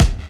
0206 DR.LOOP.wav